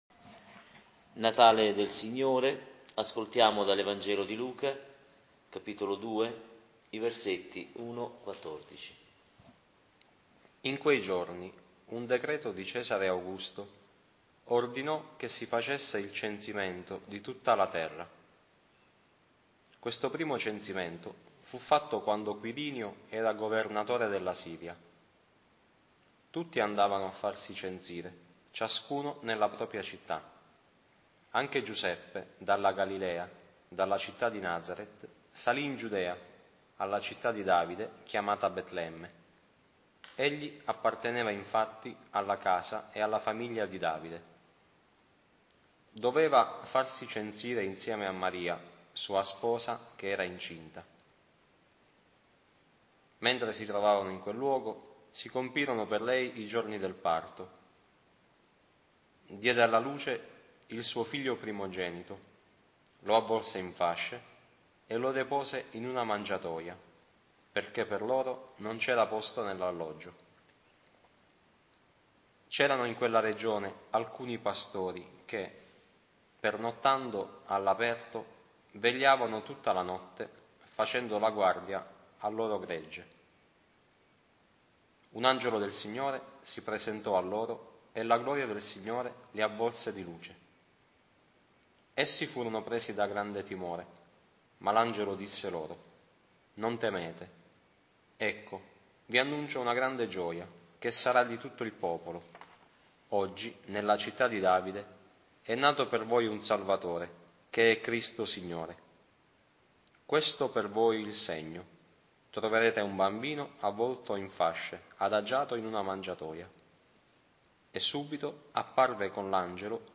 Lectio-Natale-notte-2021.mp3